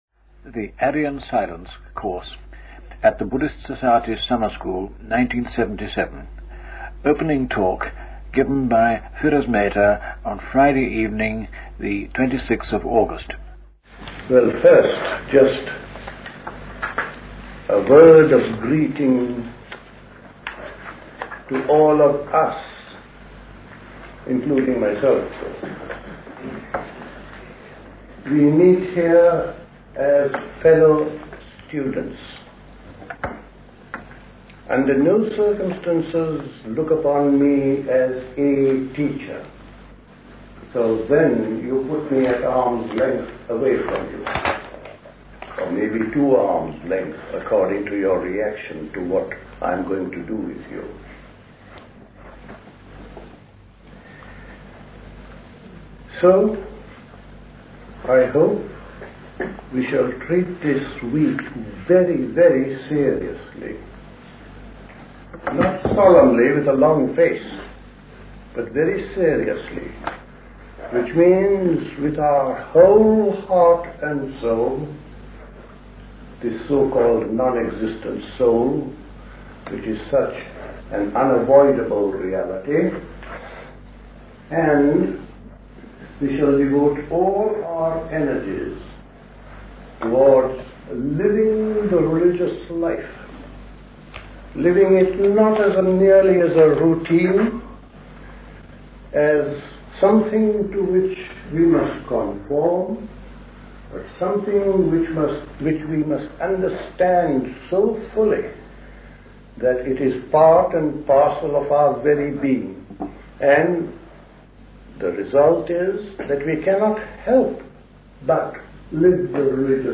Talk
High Leigh Conference Centre, Hoddesdon, Hertfordshire